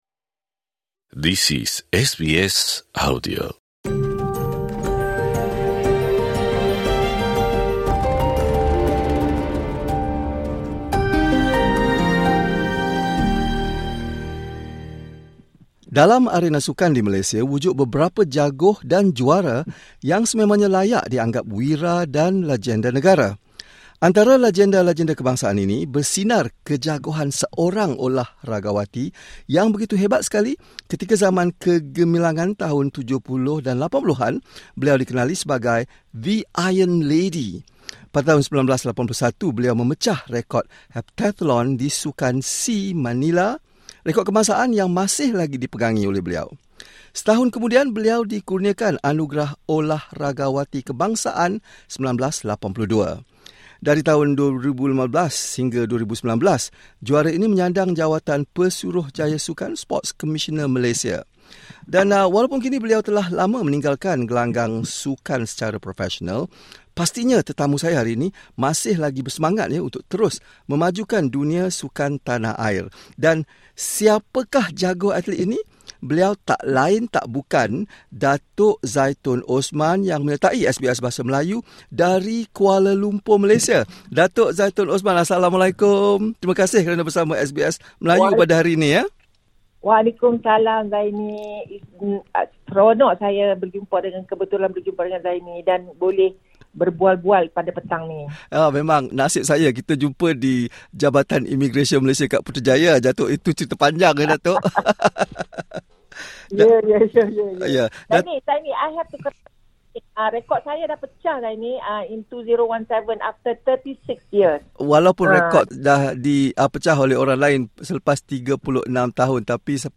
temubual